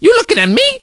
crow_get_hit_05.ogg